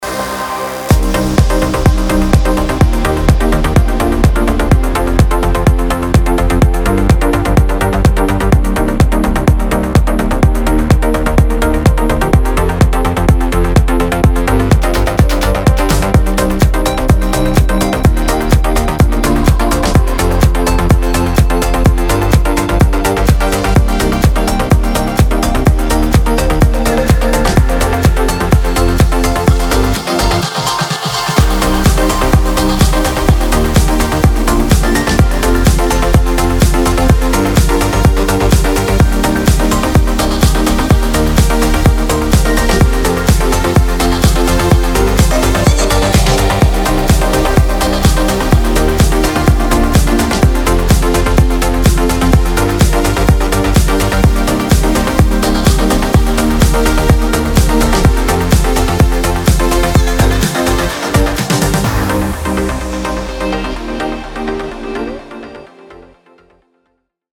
dance
Electronic
EDM
без слов
club
Style: Trance